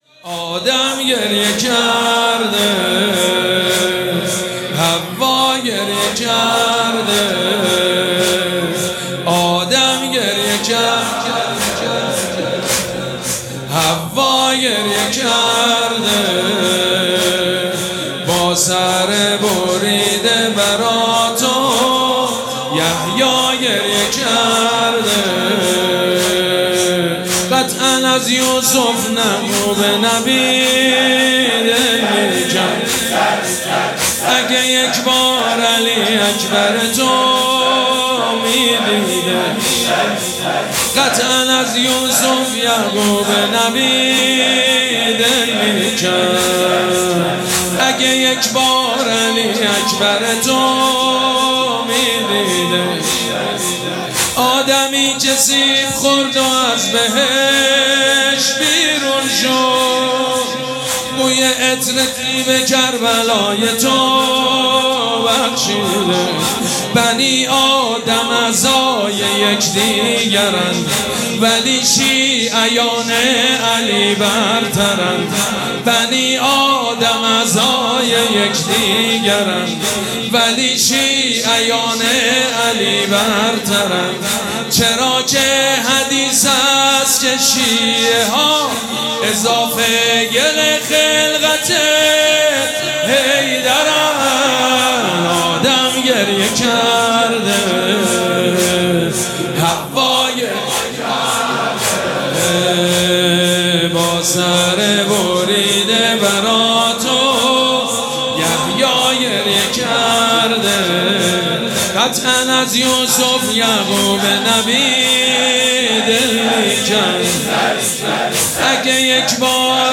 مراسم عزاداری شب دهم محرم الحرام ۱۴۴۷
حاج سید مجید بنی فاطمه